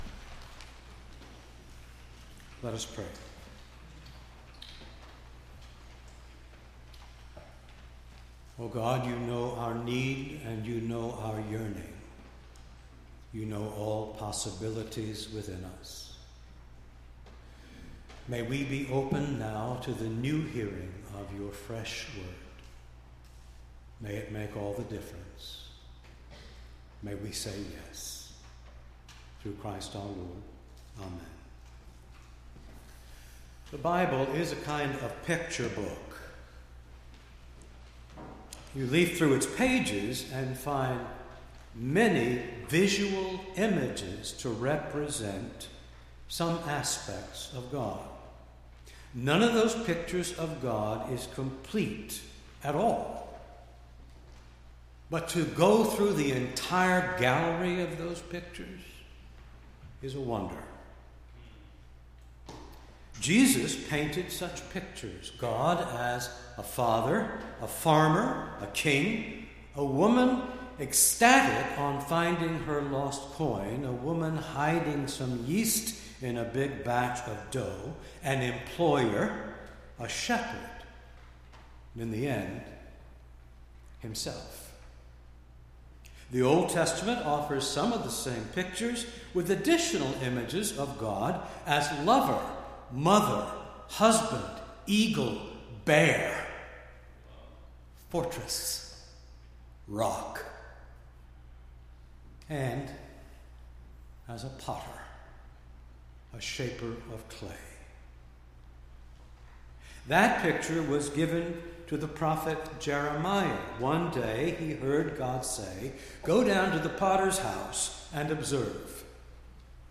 9-4-16-sermon.mp3